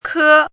chinese-voice - 汉字语音库
ke1.mp3